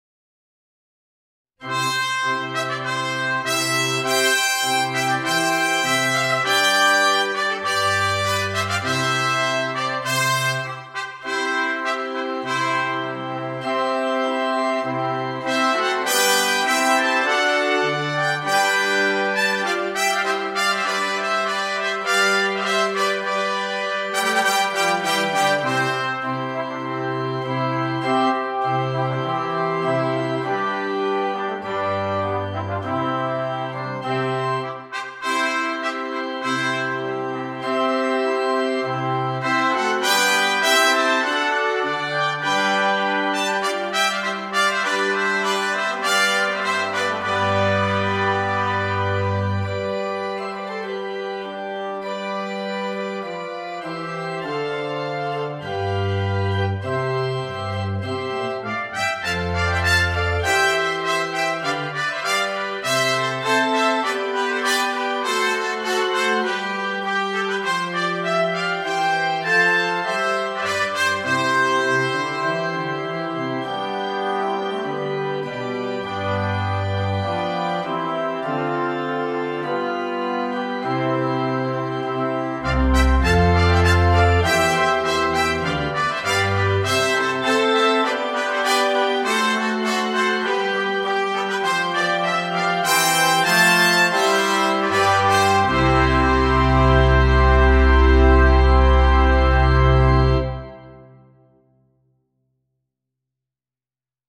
mit Orgel
ohne Soloinstrument
Klassik
Stimme 1: B-Trompete, B-Cornet
Stimme 3: Waldhorn in F
Stimme 4: Posaune, Euphonium – Bass-Schlüssel
Orgelbegleitung